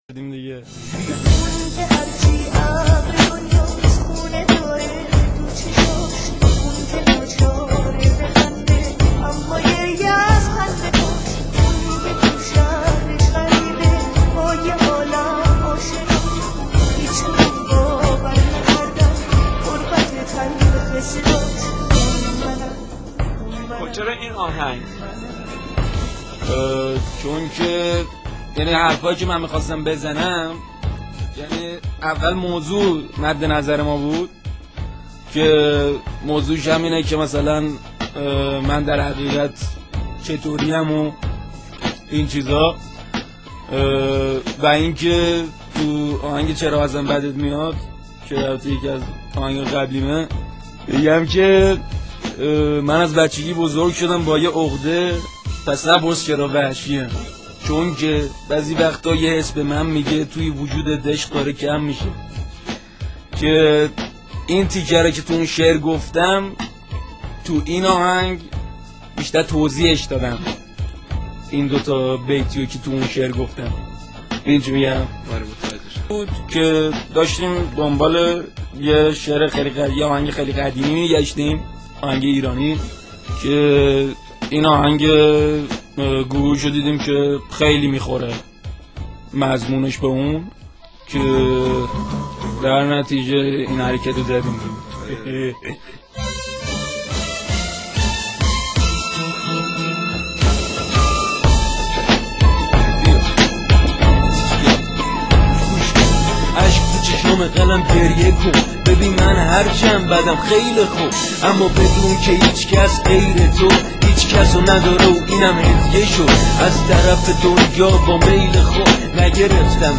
یه تیکه از مصاحبه با هیچکس